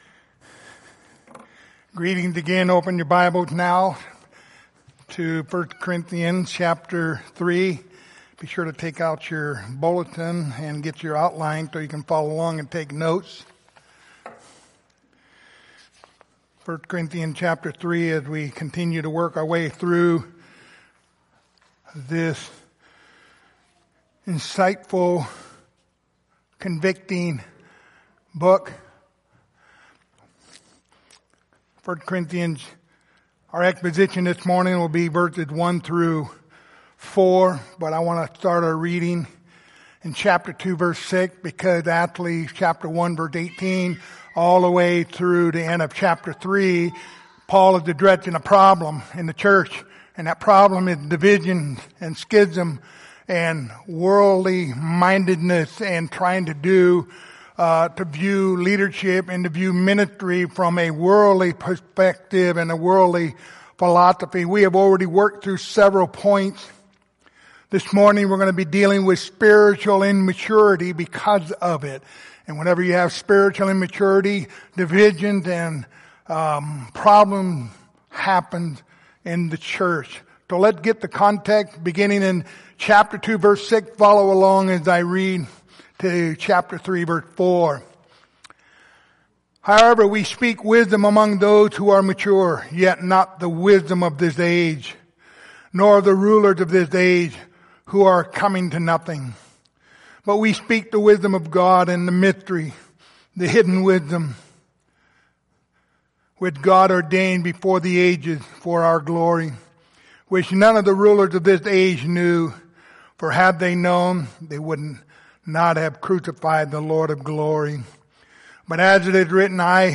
1 Corinthians Passage: 1 Corinthians 3:1-4 Service Type: Sunday Morning Topics